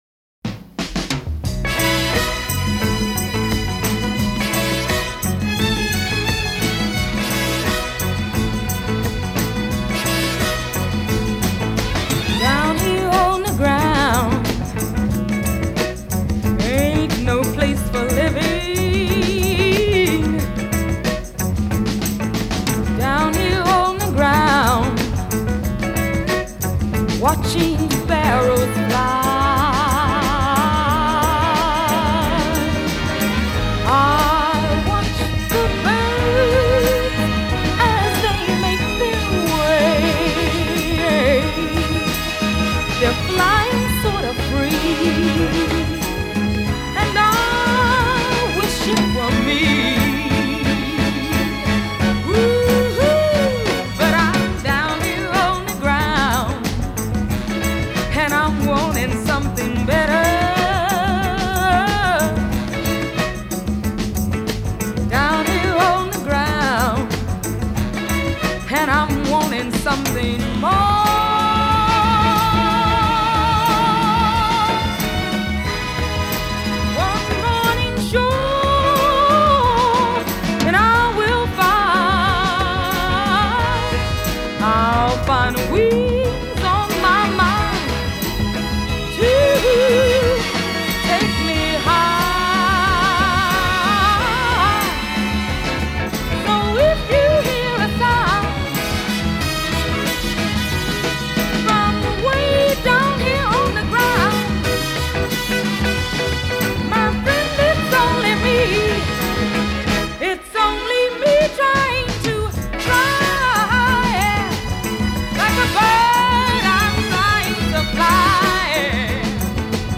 Genre: Funk / Soul